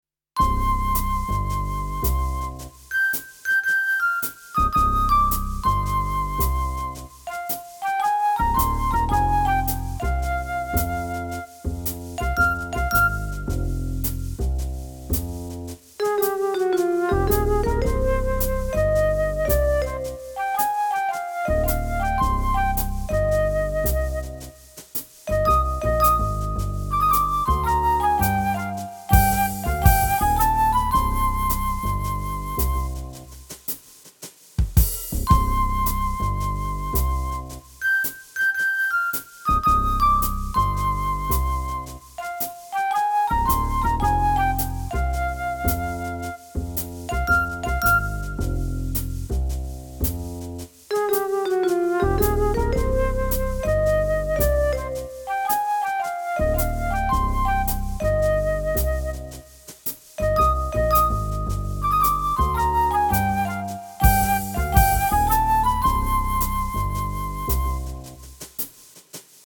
Home > Music > Jazz > Bright > Smooth > Laid Back